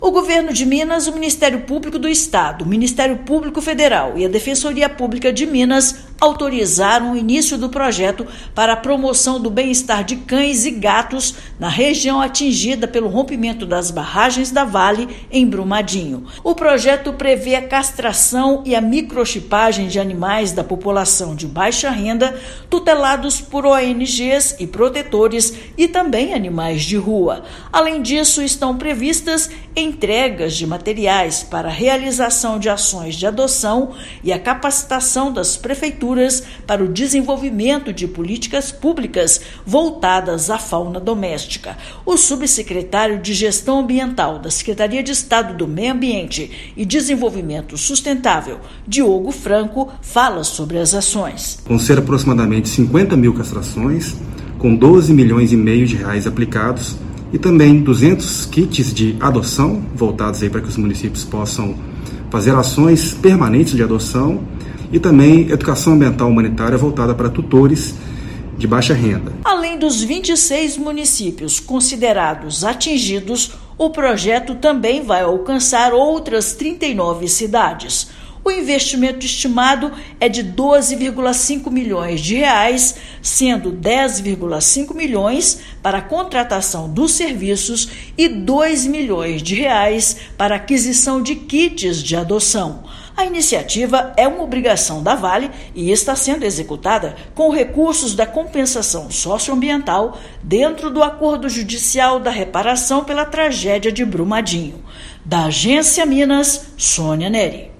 Iniciativa vai atender animais com castração e microchipagem, além de campanhas para adoção e capacitação de prefeituras para o desenvolvimento de políticas públicas voltadas à fauna doméstica. Ouça matéria de rádio.